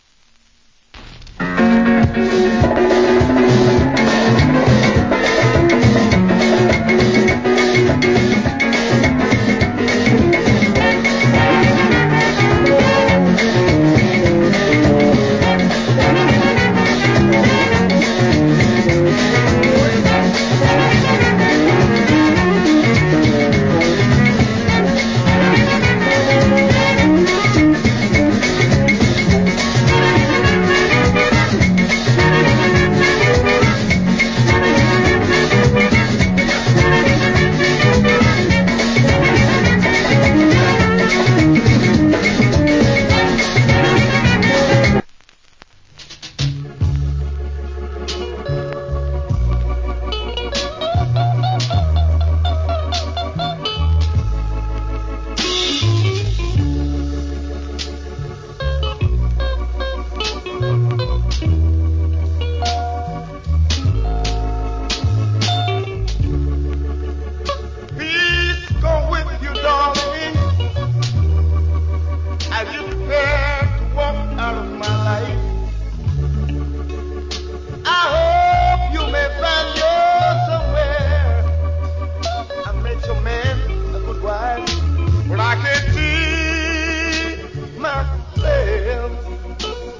Wicked Calypso Inst.